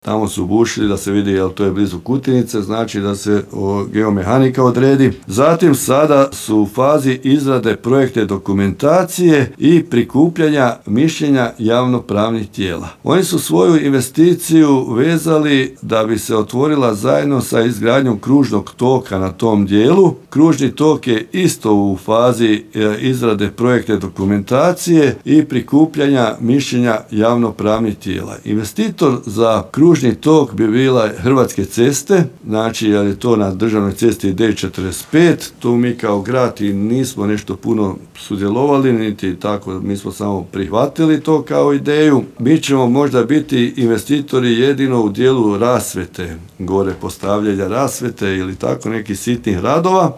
Takva je situacija i sa prostorom bivše stolarije gdje bi trebao biti izgrađen Retail Park, navodi gradonačelnik i dodaje kako je već odrađena faza ispitivanja tla